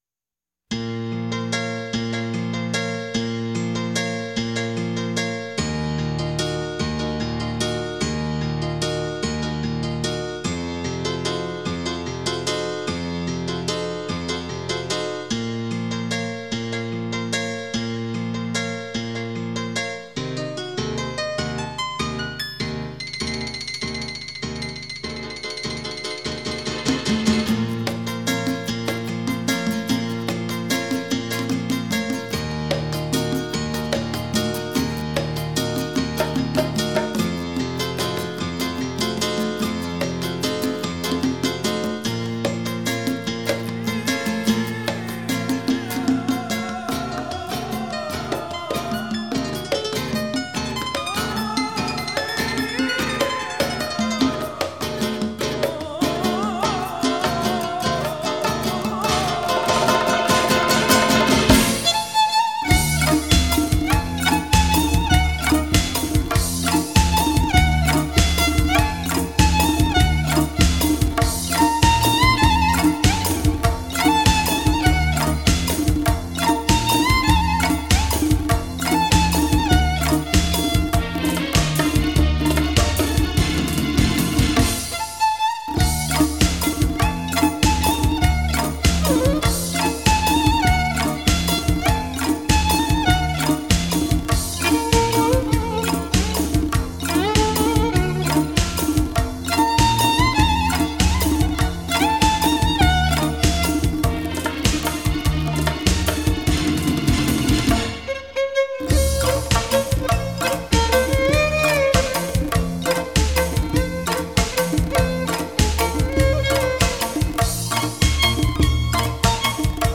این موسیقی بی کلام می باشد